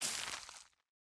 bonemeal4.ogg